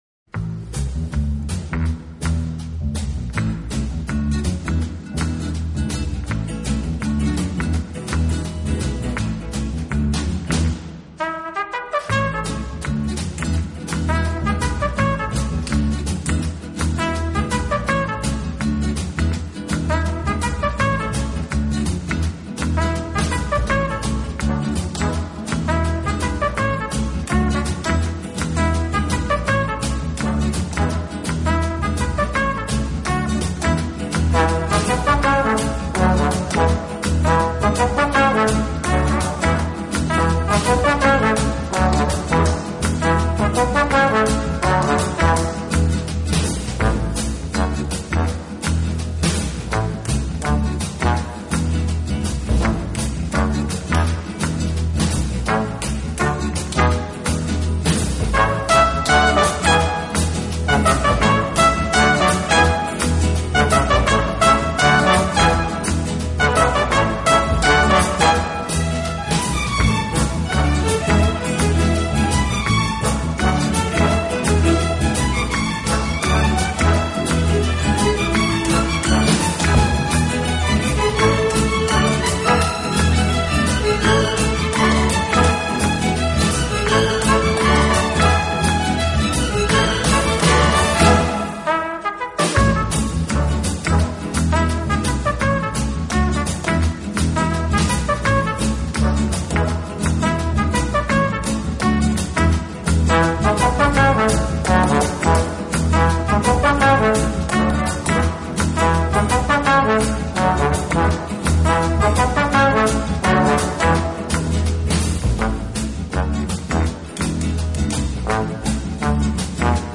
【轻音乐】
小号的音色，让他演奏主旋律，而由弦乐器予以衬托铺垫，音乐风格迷人柔情，声情并
温情、柔软、浪漫是他的特色，也是他与德国众艺术家不同的地方。